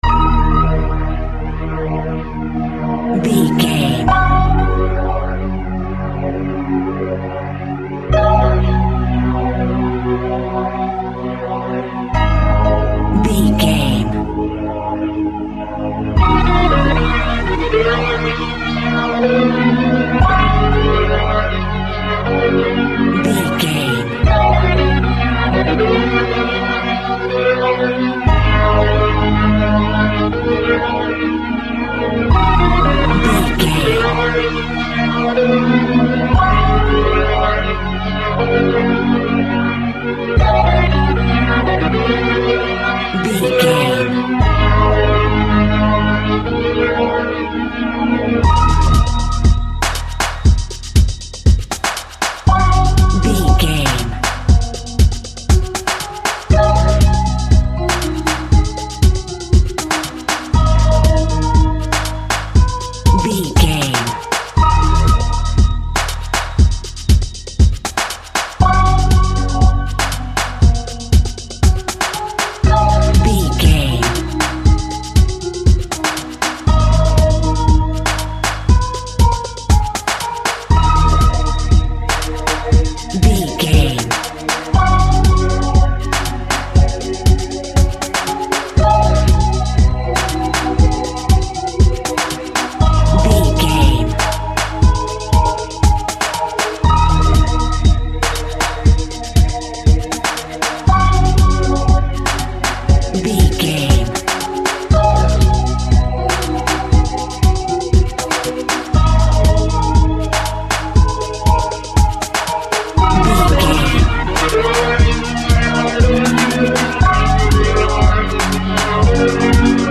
Hip Hop and Reggae Fusion.
Aeolian/Minor
E♭
chilled
laid back
hip hop drums
hip hop synths
piano
hip hop pads